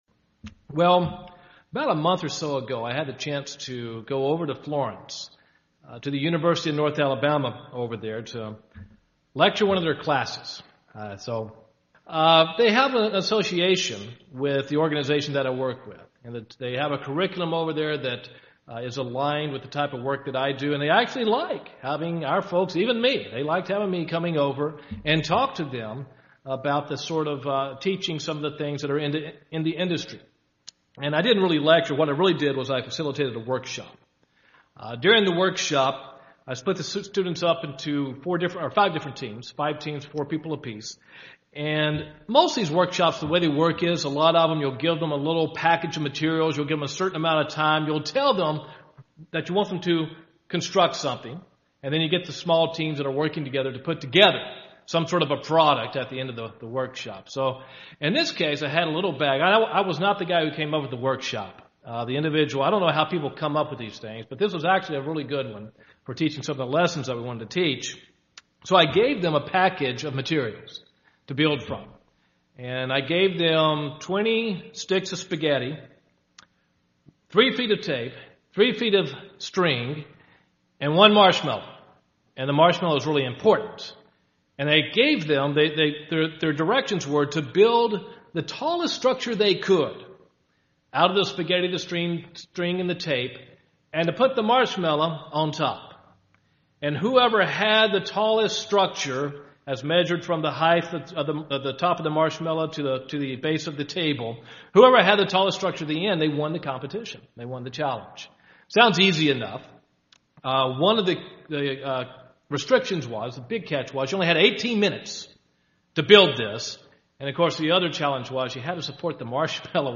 This sermon discusses the importance of developing the spirit which God gave to us. It shows how we have a small amount of time to produce a spirit of eternal value while revealing how that end product is the most important accomplishment of our life.